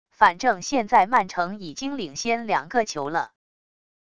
反正现在曼城已经领先两个球了wav音频生成系统WAV Audio Player